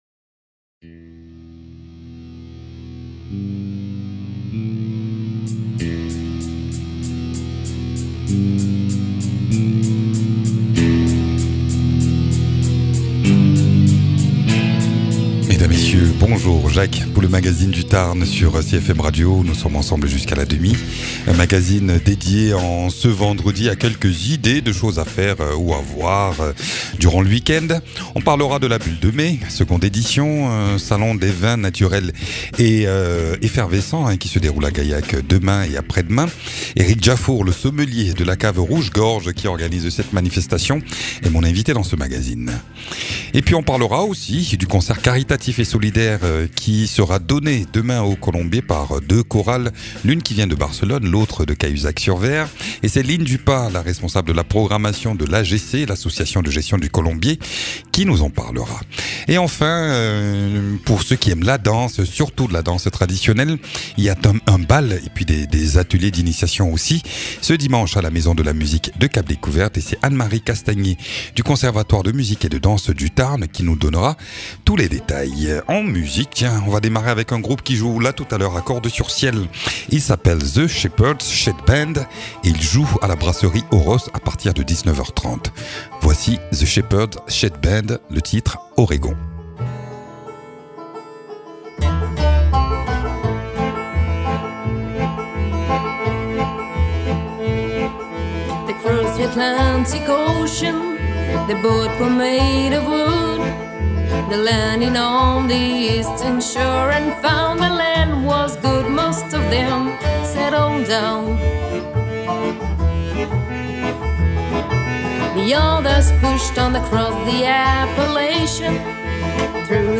En musique on écoute notamment un extrait de l’EP de The